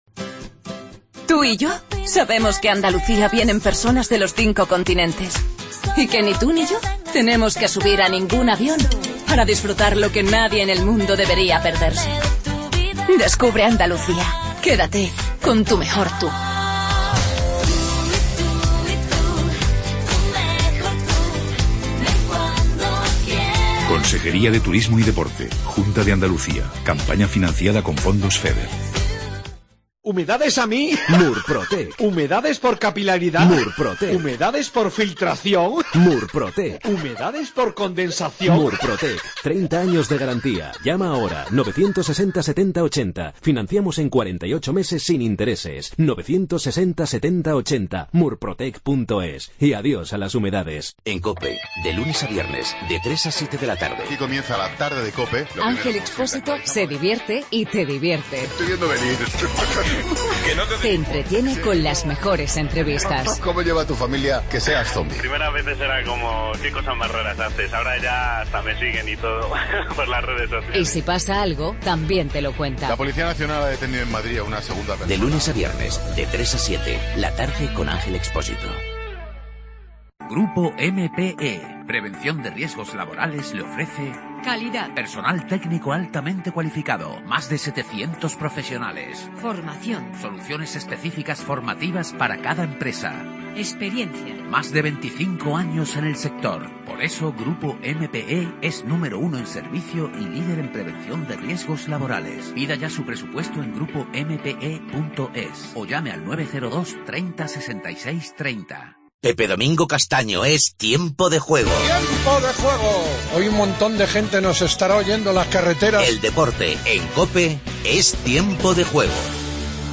INFORMATIVO LOCAL MATINAL 8:20